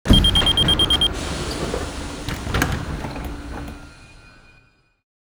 doorsopen2.wav